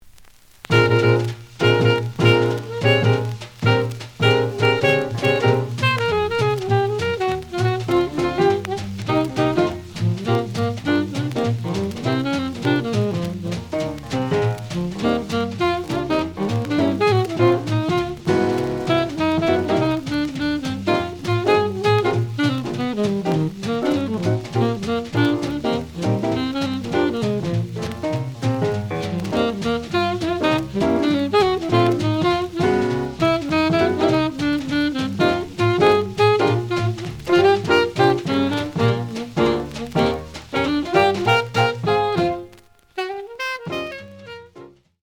The audio sample is recorded from the actual item.
●Genre: Cool Jazz
Slight noise on B side.